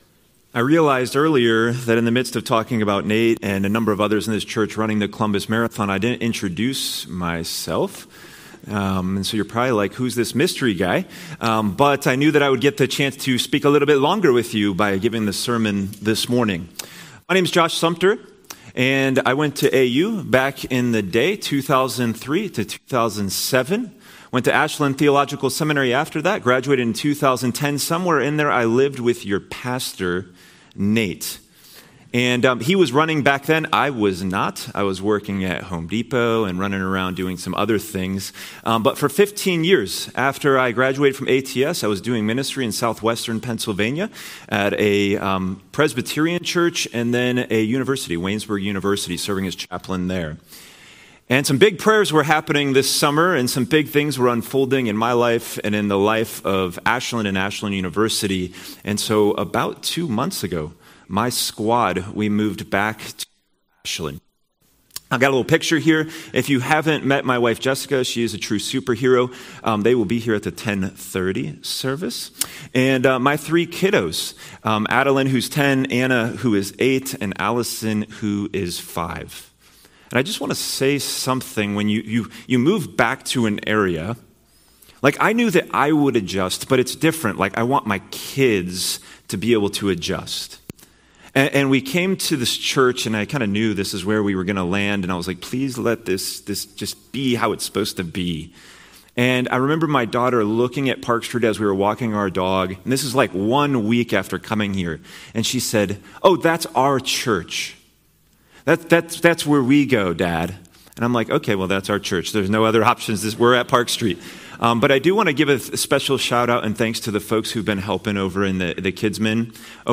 Sermons - Park Street Brethren Church